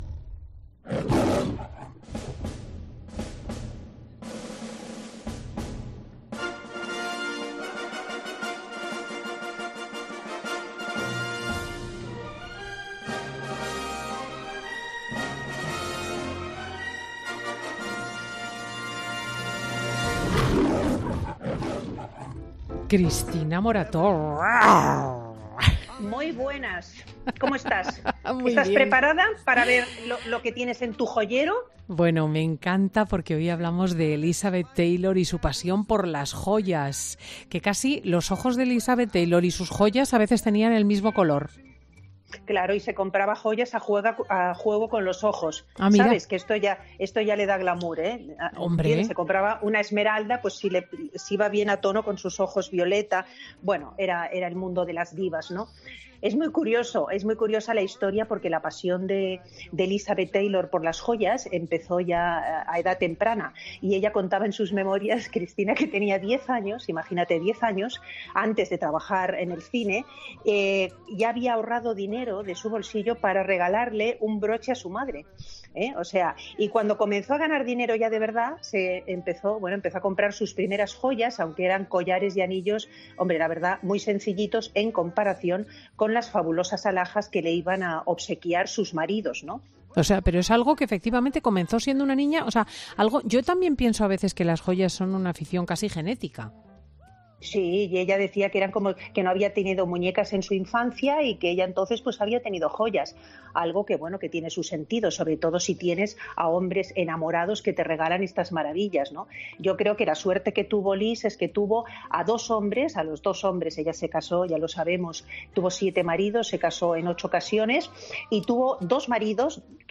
Siempre, de la mano y la voz de Cristina López Schlichting, en cuyo dilatado currículum vitae se incluyen sus labores de articulista y reportera en los principales periódicos de España (ABC, El Mundo o La Razón o su papel de tertuliana de televisión.